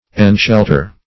enshelter - definition of enshelter - synonyms, pronunciation, spelling from Free Dictionary Search Result for " enshelter" : The Collaborative International Dictionary of English v.0.48: Enshelter \En*shel"ter\, v. t. To shelter.